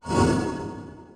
Trimmed-Holy Buffs
sfx updates